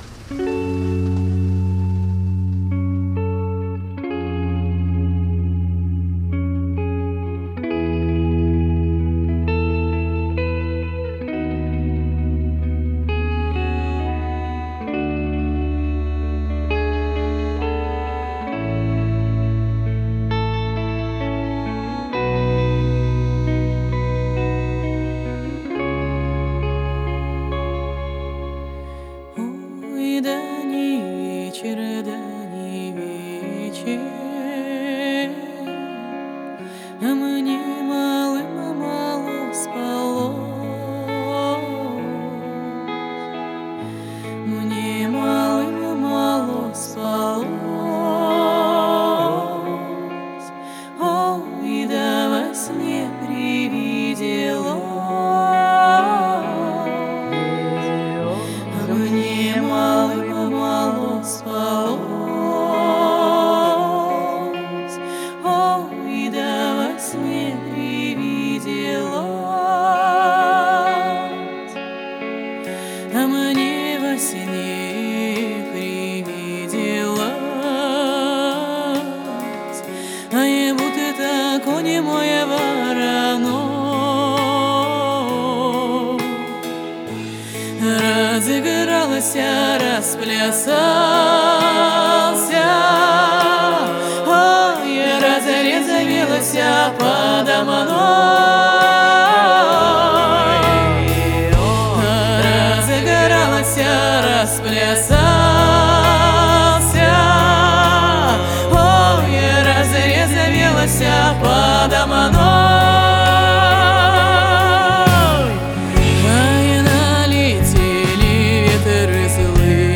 Этническая музыка